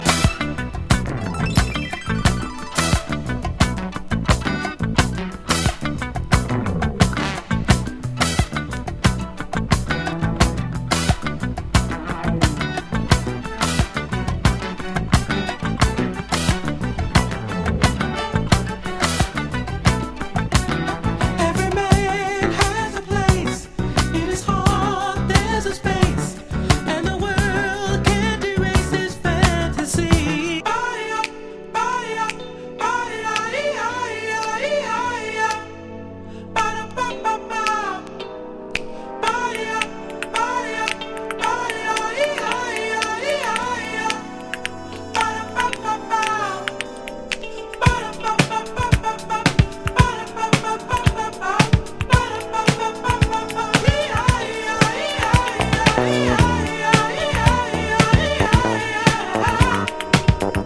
オールタイム悶絶ファルセット・コーラス・ディスコ